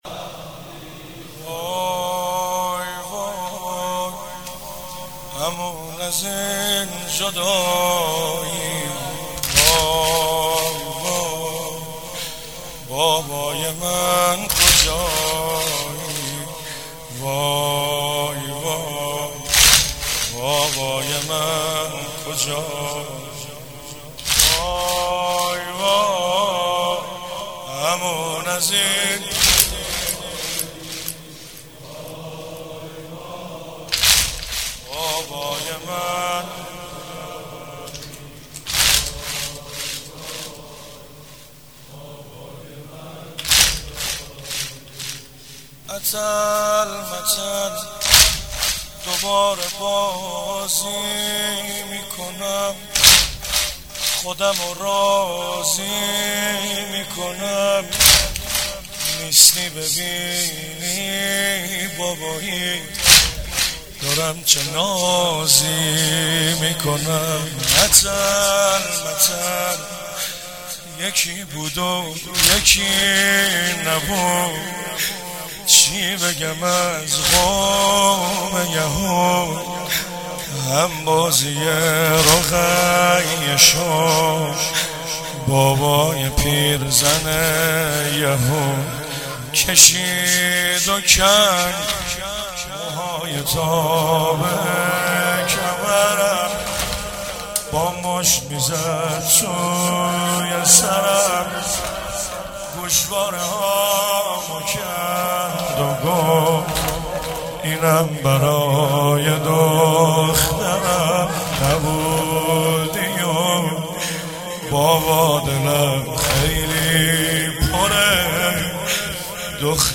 05 heiate alamdar mashhad alreza.mp3